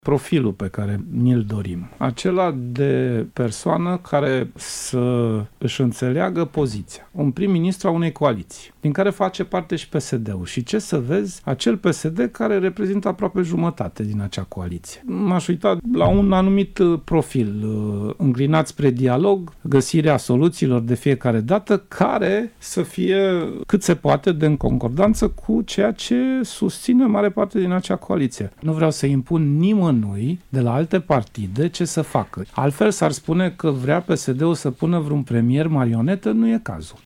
„PSD va merge la Cotroceni cu bună credință”, a spus liderul social-democraților într-un interviu acordat Radio România Actualități.